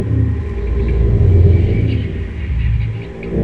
• Isolating the high tones of the SFX that play in Morrowind when standing close to the Heart of Lorkhan reveals the sound of whispering echoing alongside the heartbeat: (listen